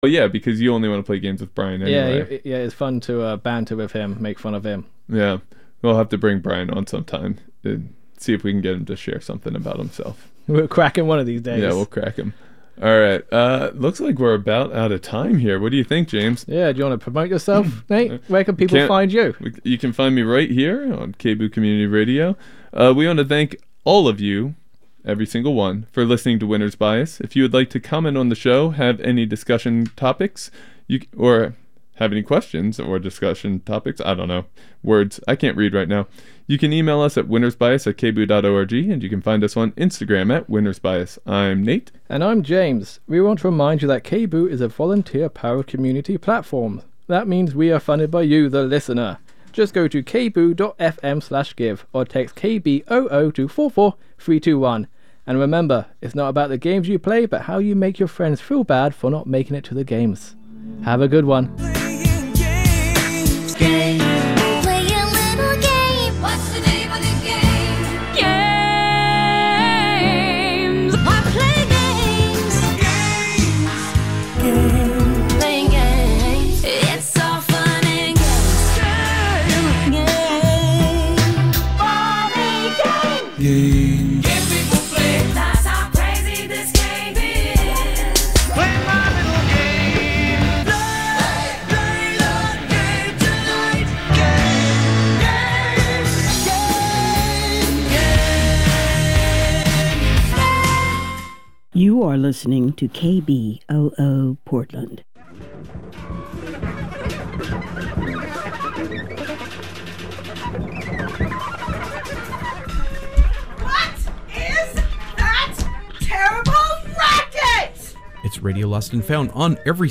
Radio Schedule